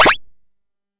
monster_attack.mp3